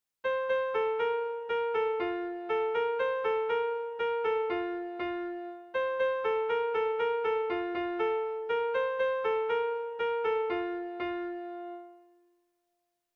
Bertso melodies - View details   To know more about this section
ABAB